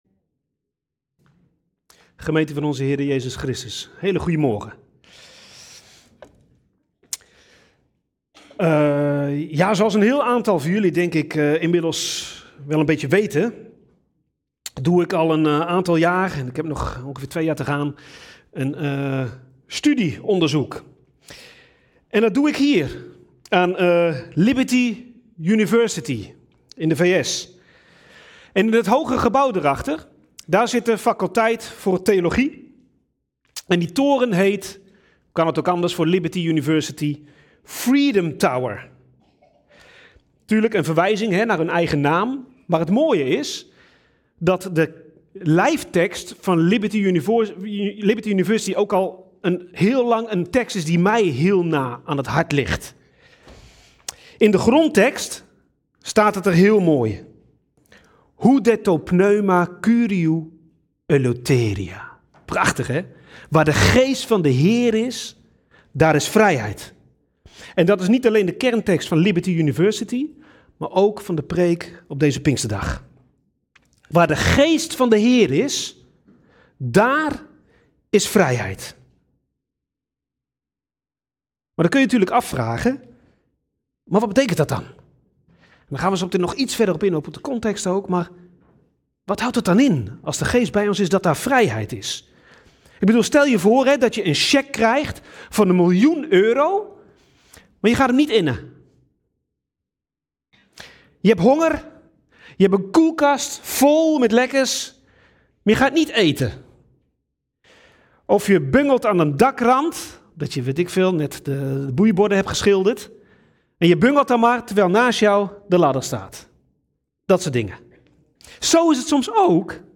Pinksterdienst